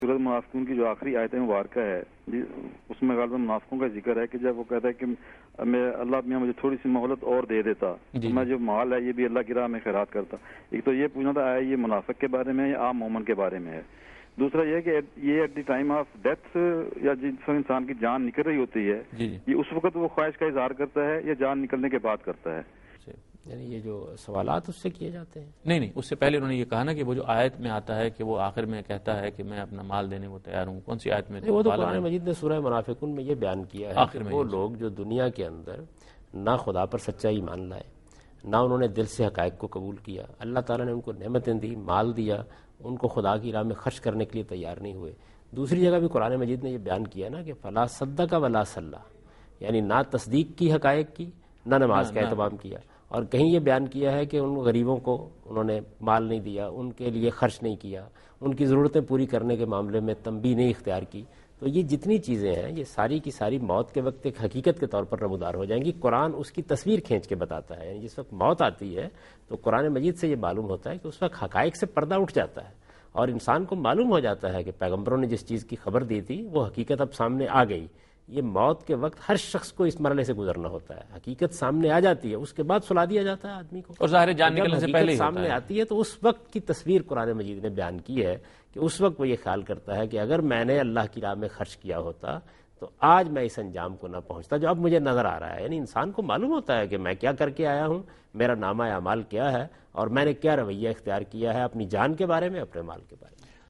Category: TV Programs / Dunya News / Deen-o-Daanish /
Javed Ahmad Ghamidi Answer the Question about Remorse after Death In Program Deen o Danish
دین ودانش کے اس پروگرام میں جاوید احمد صاحب غامدی موت کے بعد گناہوں پر افسوس سے متعلق سوال کا جواب دے رہے ہیں